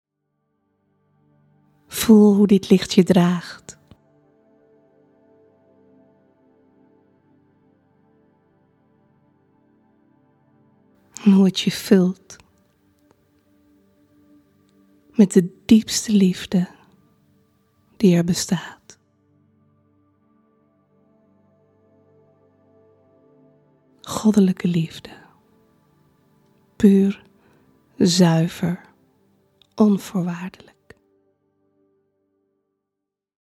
Visualisatie “Verbind je met de Liefde die JE BENT”